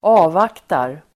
Uttal: [²'a:vak:tar]